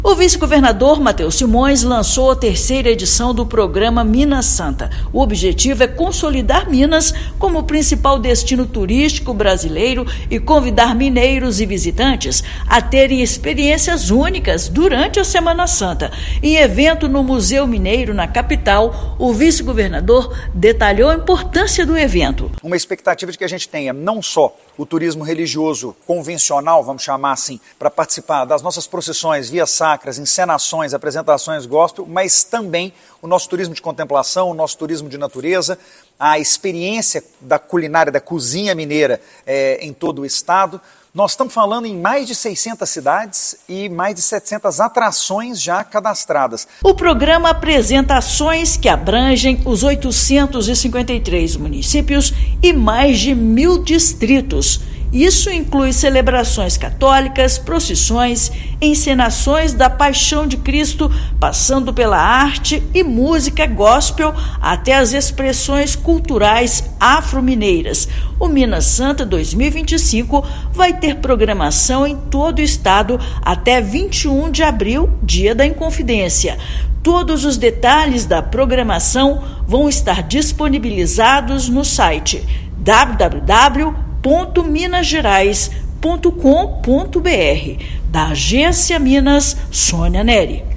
Programação reúne celebrações e rotas turísticas abrangendo os 853 municípios e mais de mil distritos; ano passado, cerca de 500 mil pessoas visitaram o estado na Semana Santa. Ouça matéria de rádio.